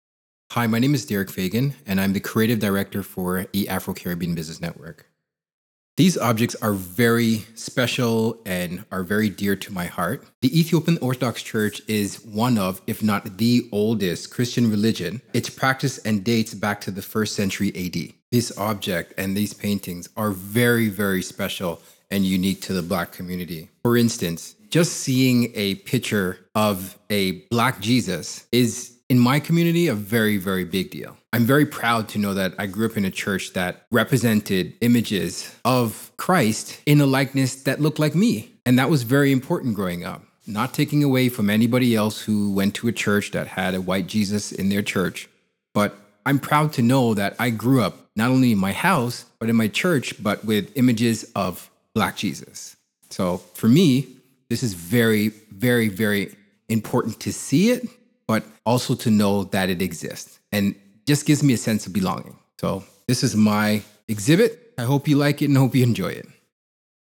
In the interviews below, the curators speak about the significance of their chosen object.